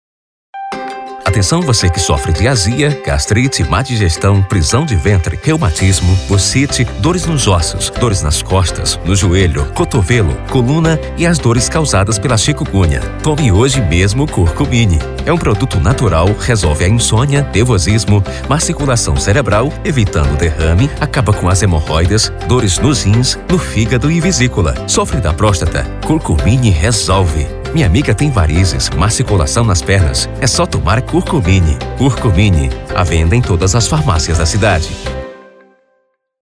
Spot - Padrão/Sério :